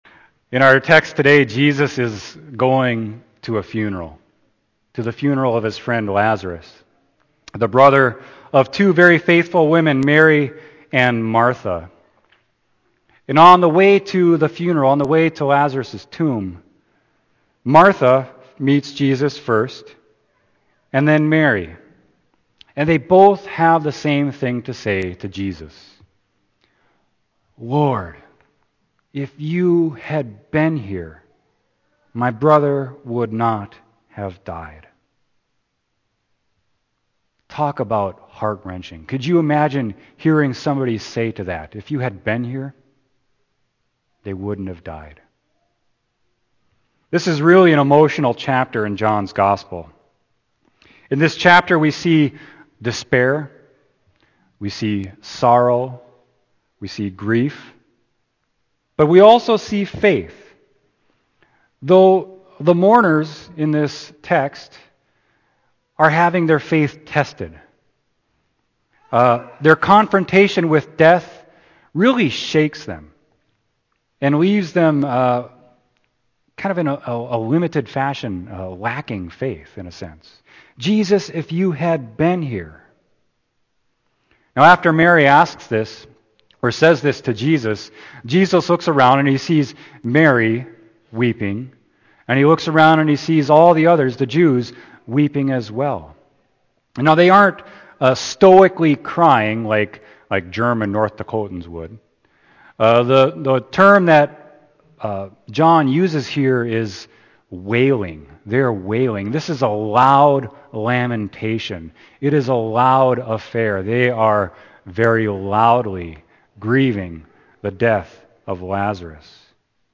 Sermon: John 11.32-44